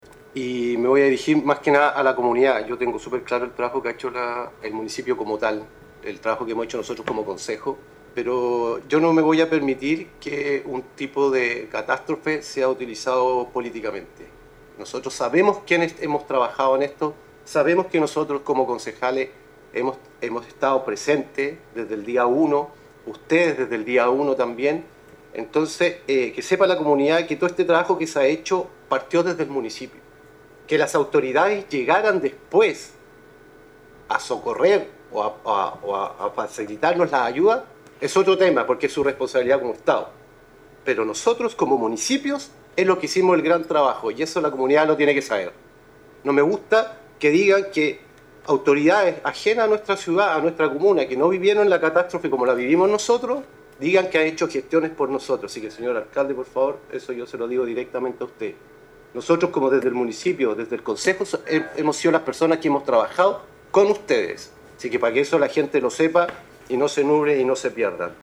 Durante el debate en la mesa del concejo, el concejal Jaime Chepillo hizo referencia a aprovechamiento político de esta emergencia por parte de autoridades de nivel provincial, regional y nacional.